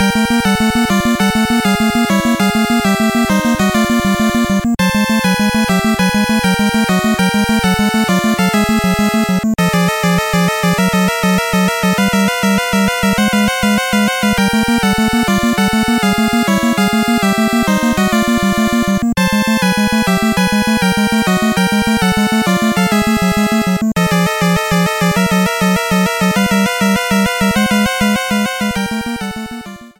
battle theme
Clipped to 30 seconds and applied fade-out.